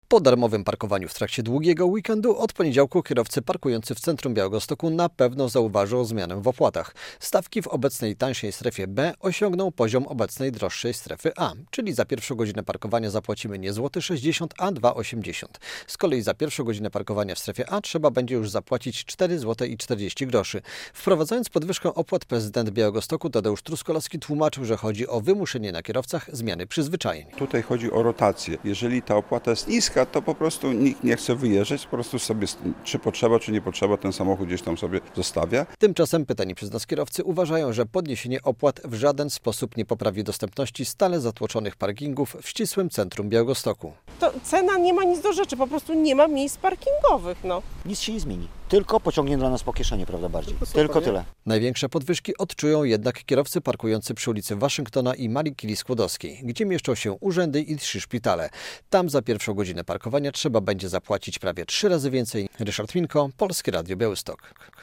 Radio Białystok | Wiadomości | Wiadomości - Parkowanie w Białymstoku droższe o prawie 60 proc.